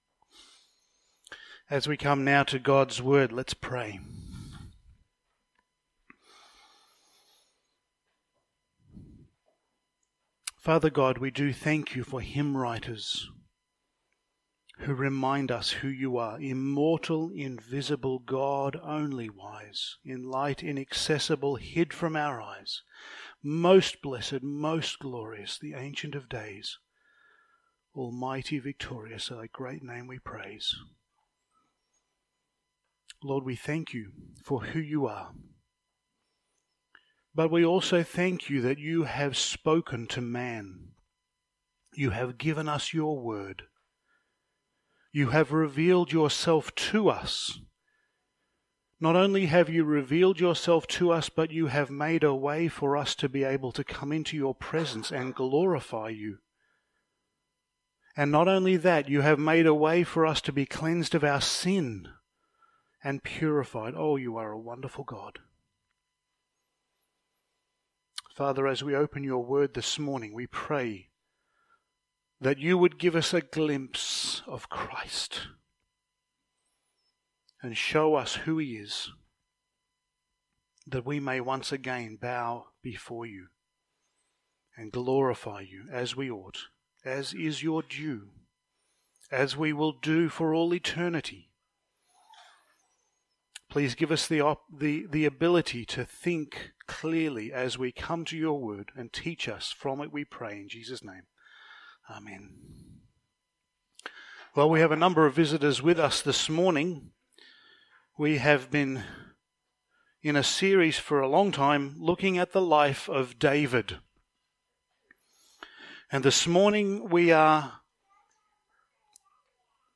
Passage: 2 Samuel 21:15-22 Service Type: Sunday Morning